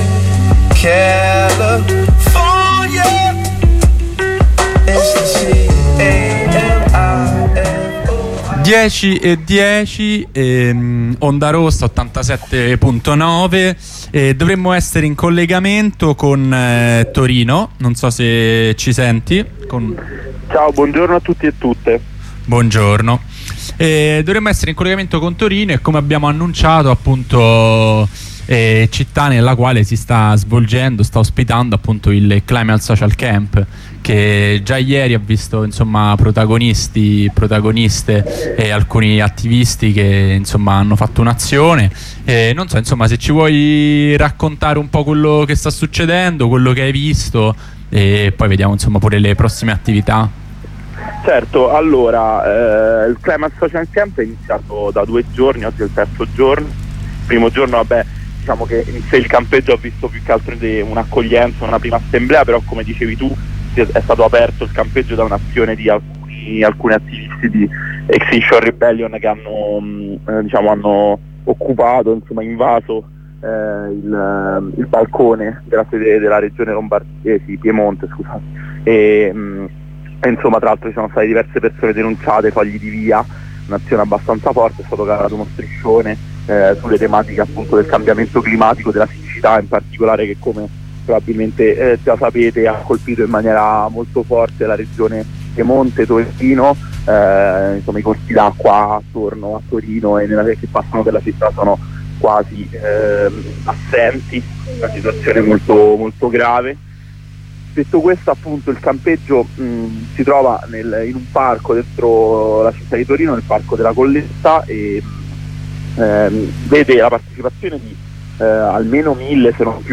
Comunicazione telefonica